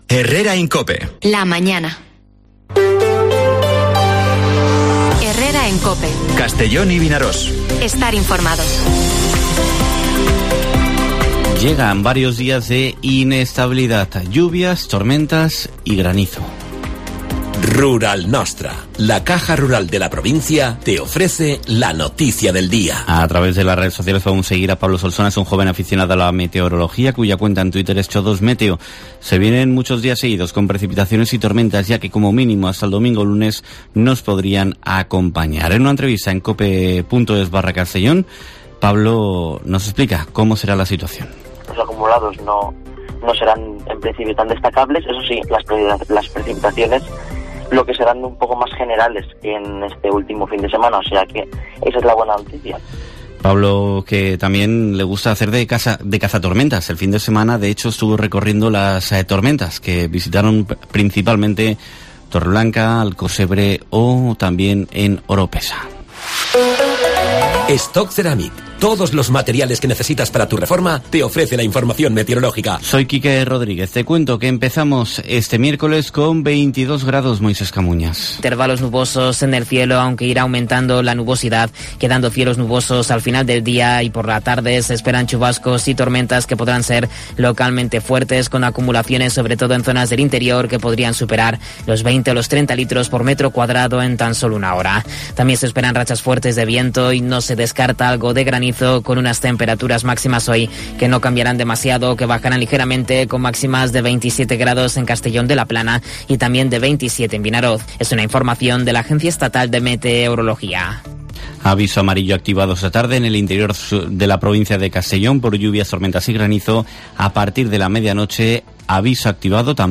Informativo Herrera en COPE en la provincia de Castellón (21/09/2022)